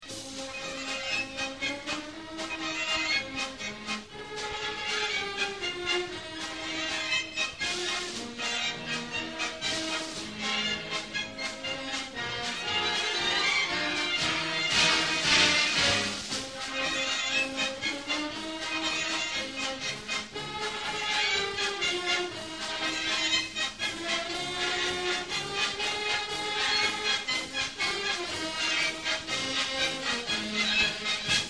Die Stücke wurden während des Konzerts 2003 live (unplugged) aufgenommen.